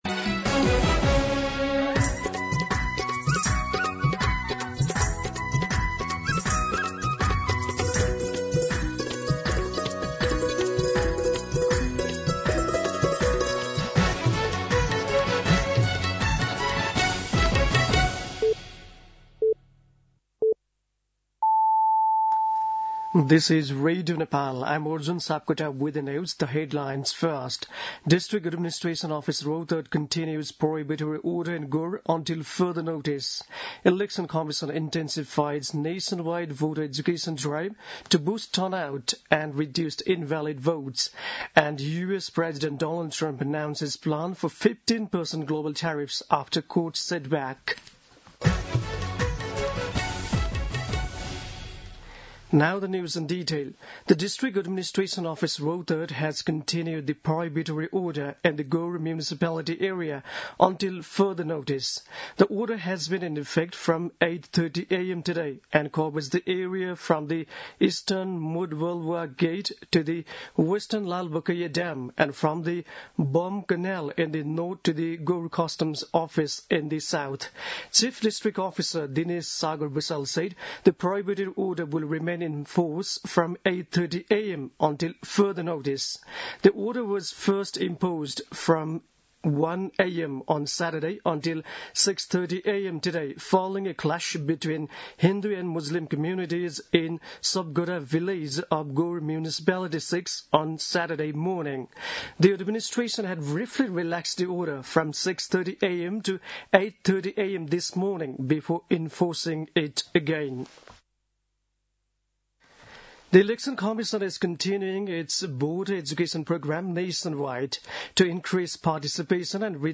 दिउँसो २ बजेको अङ्ग्रेजी समाचार : १० फागुन , २०८२
2-pm-English-News-2.mp3